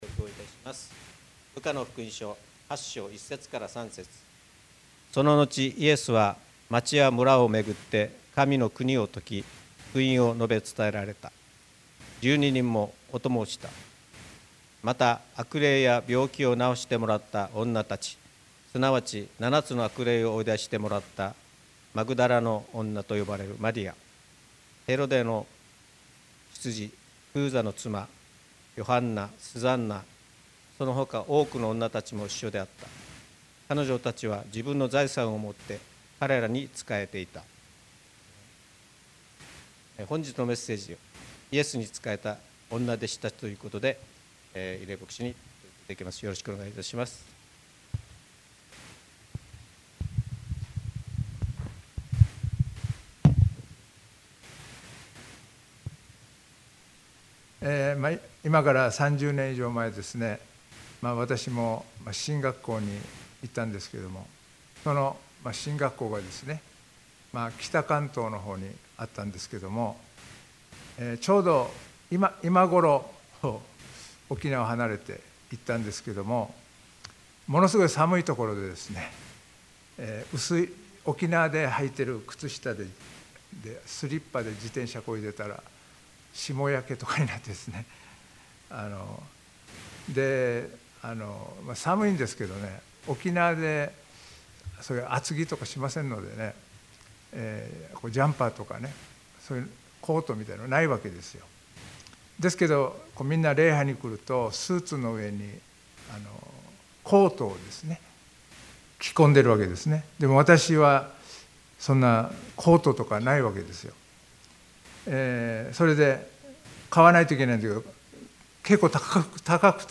2026年2月1日礼拝メッセージ